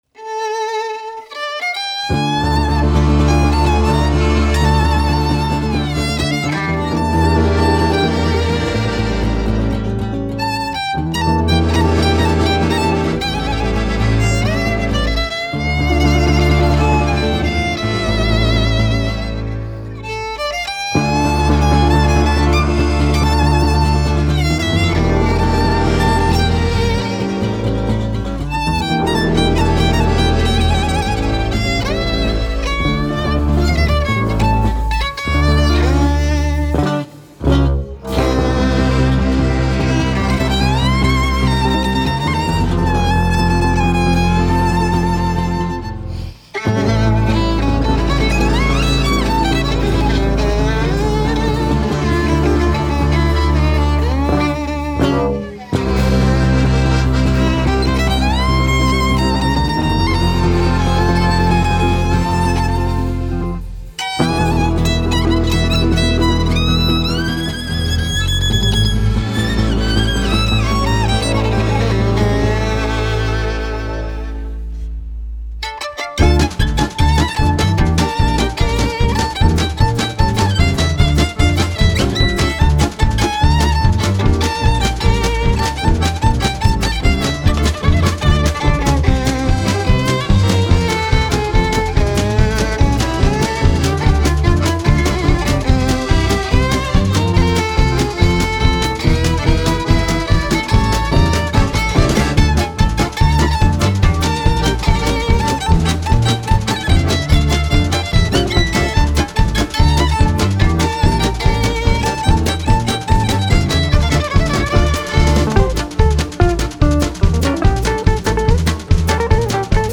Guitar/D'rbukka
Violin/Viola
Accordian
Double Bass
Drums